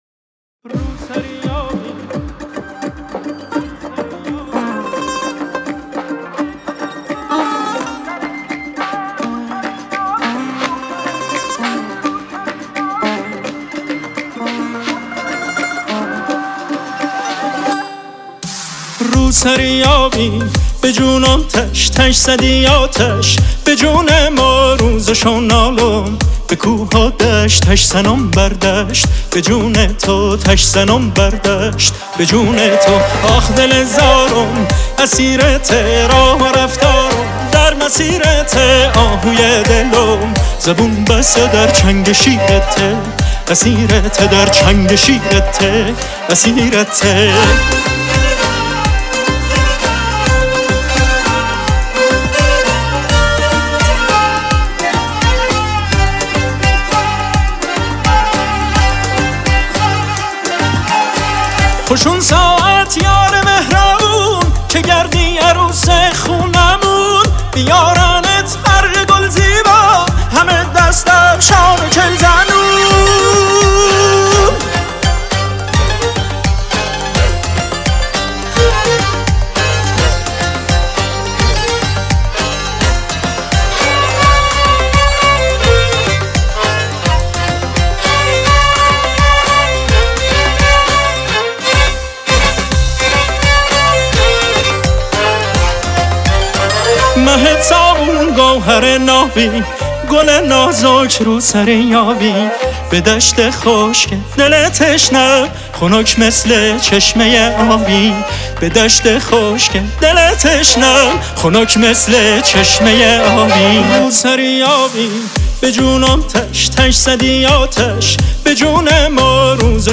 دسته : بی کلام